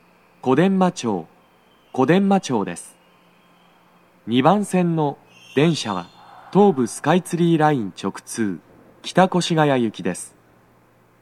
スピーカー種類 BOSE天井型
足元注意喚起放送の付帯は無く、フルの難易度は普通です。鳴動のタイミングは、完全に停車しきる直前です。
2番線 北千住・南栗橋方面 到着放送 【男声